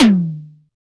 Index of /90_sSampleCDs/Roland L-CD701/DRM_Analog Drums/TOM_Analog Toms
TOM DDR TOM1.wav